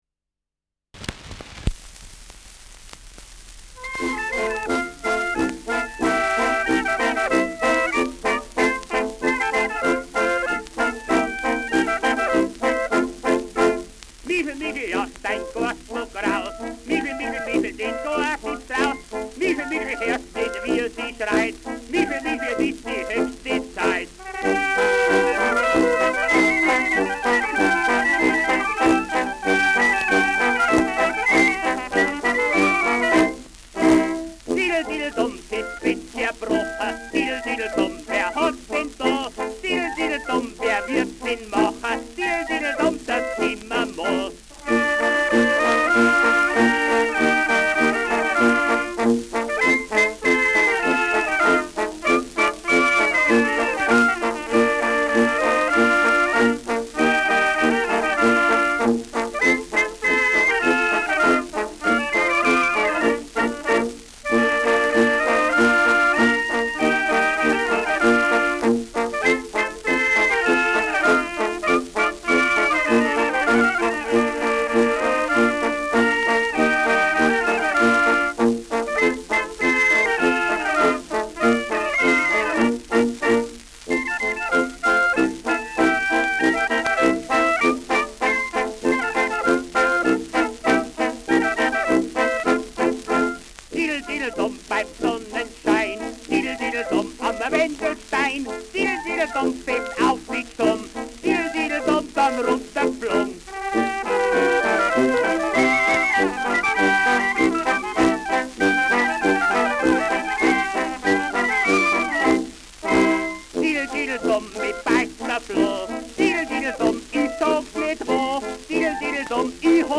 Schellackplatte
Leicht abgespielt : Gelegentliches Knacken : Schriller Klang
Dachauer Bauernkapelle (Interpretation)
[München] (Aufnahmeort)